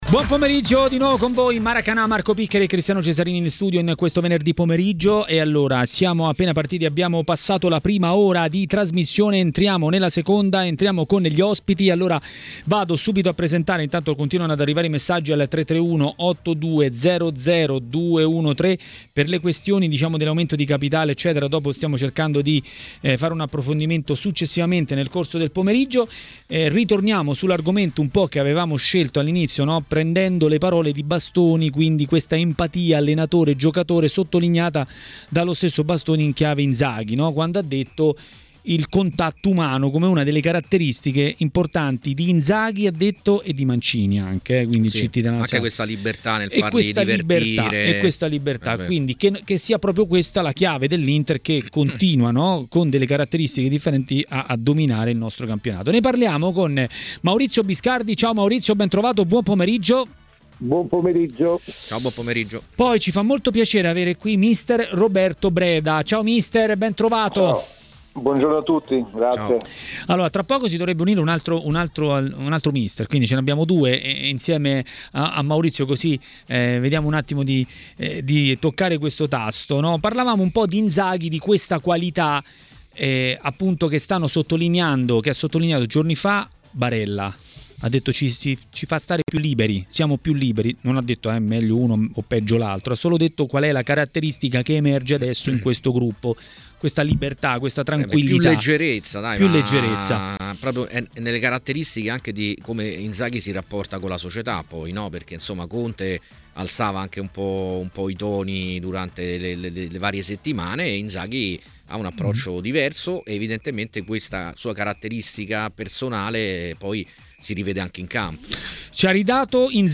Mister Roberto Breda a Maracanà, nel pomeriggio di TMW Radio, ha commentato le notizie di giornata.